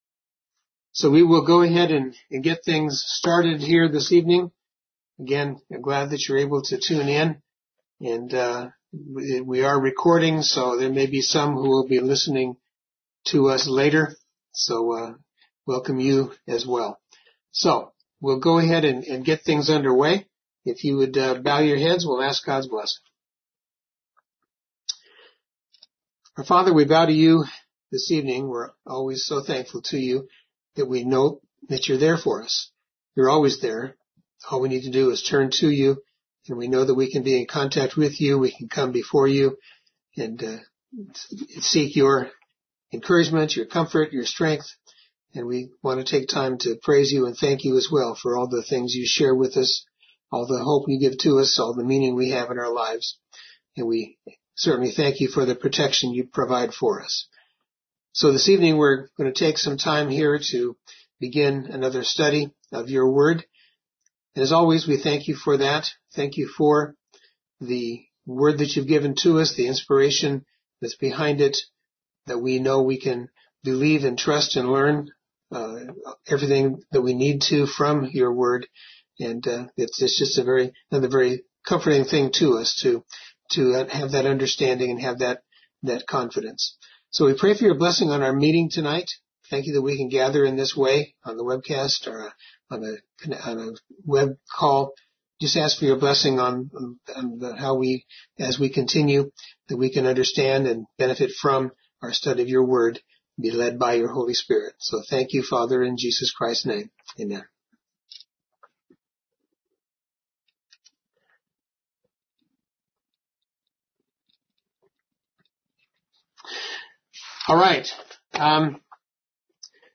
BibleStudyBuilding_the_Tabernacle_in_the_Wilderness.mp3